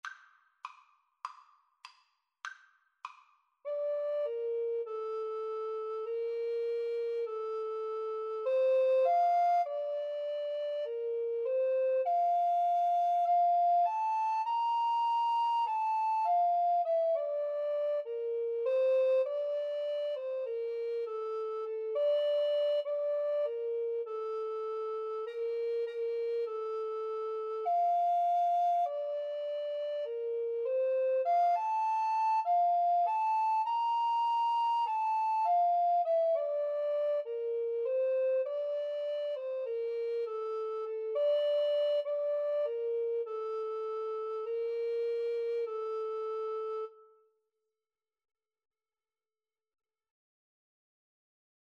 Play (or use space bar on your keyboard) Pause Music Playalong - Player 1 Accompaniment reset tempo print settings full screen
F major (Sounding Pitch) (View more F major Music for Alto Recorder Duet )
Moderato
Traditional (View more Traditional Alto Recorder Duet Music)